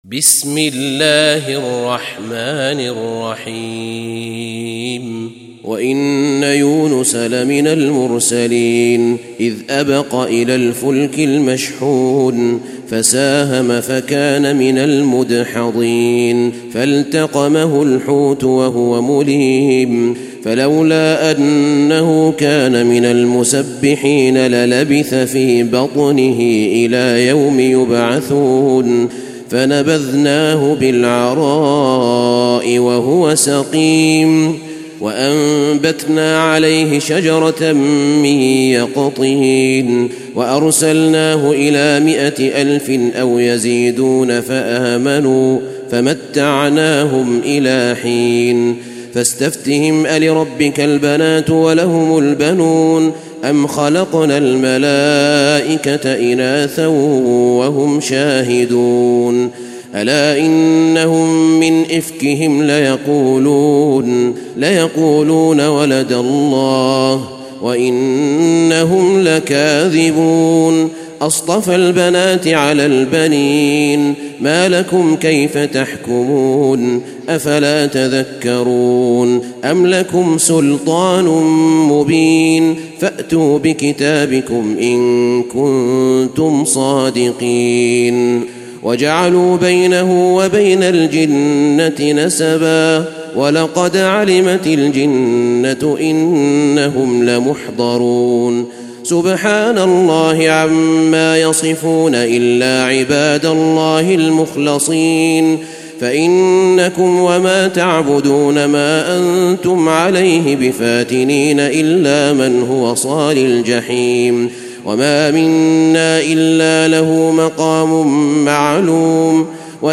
تلاوة سورة الصافات
تاريخ النشر ١ محرم ١٤٣٤ هـ المكان: المسجد النبوي الشيخ: فضيلة الشيخ أحمد بن طالب بن حميد فضيلة الشيخ أحمد بن طالب بن حميد سورة الصافات